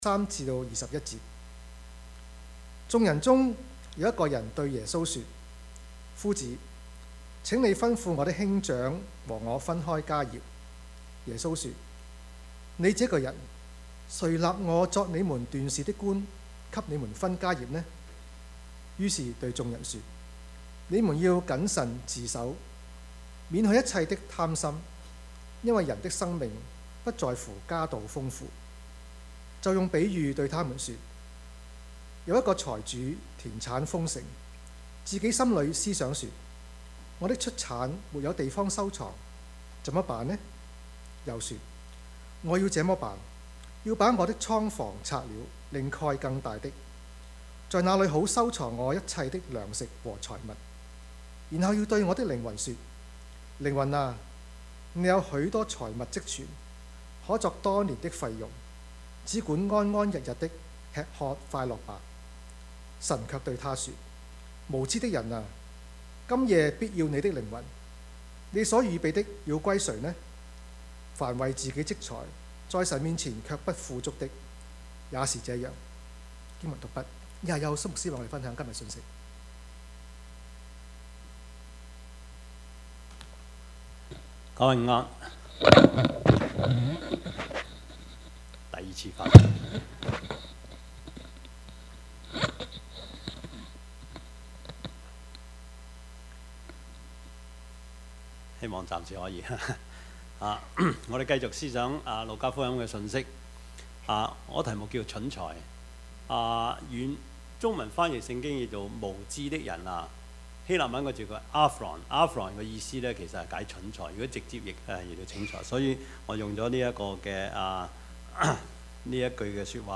Service Type: 主日崇拜
Topics: 主日證道 « 怕甚麼 人無遠慮, 必有近憂 »